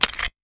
metahunt/weapon_foley_pickup_05.wav at master
weapon_foley_pickup_05.wav